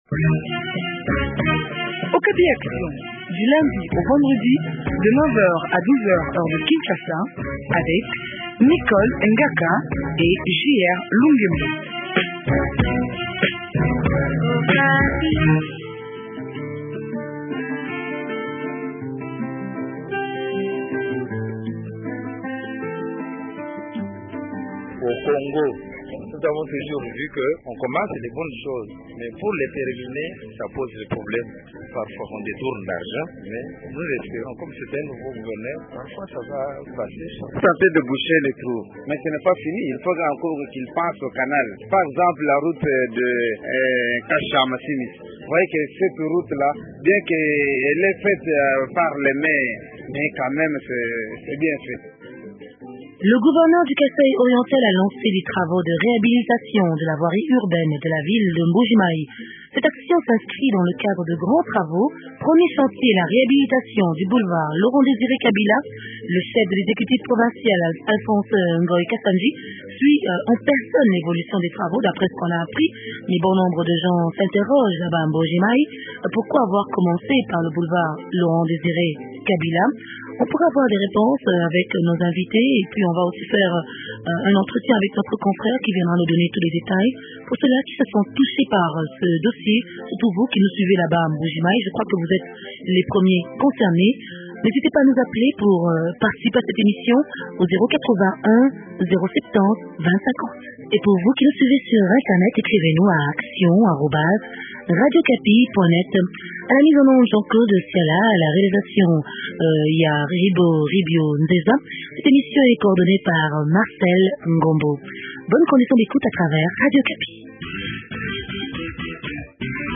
recoivent Ngoy Kasandji,gouverneur du Kasai Oriental.